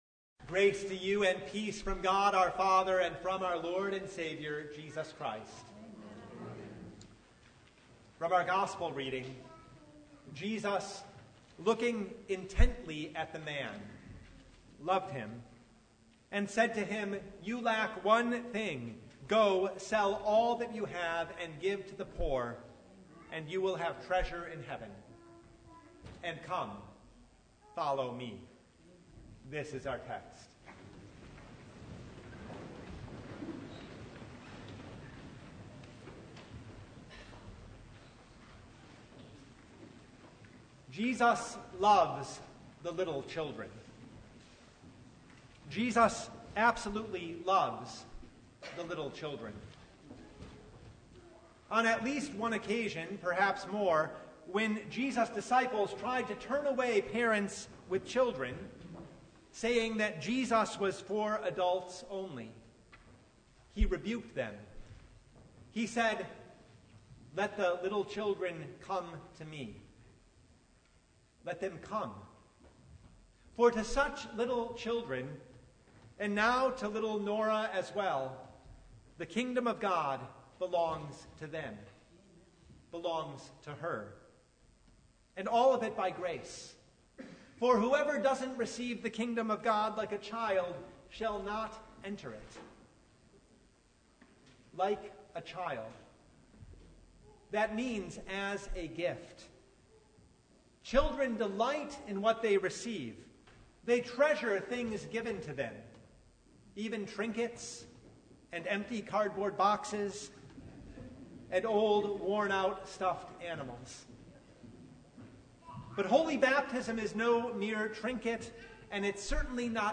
Mark 10:17–22 Service Type: Sunday It’s heartbreaking